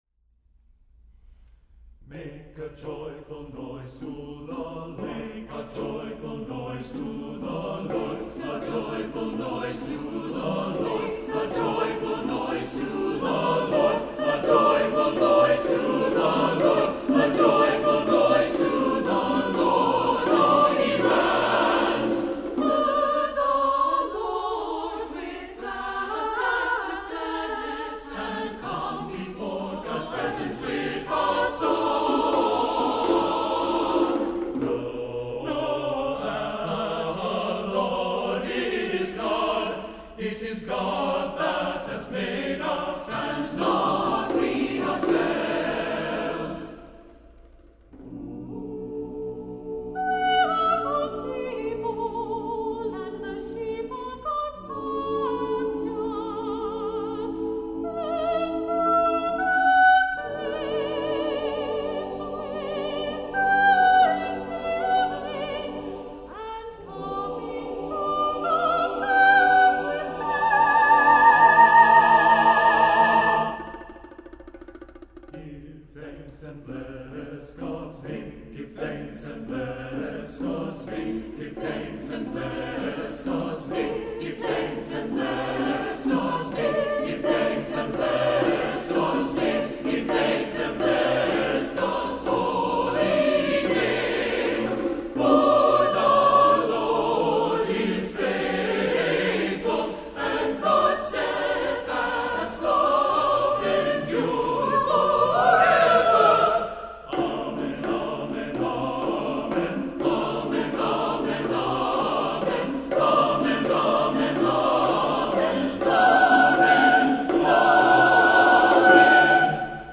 Voicing: SATB and Percussion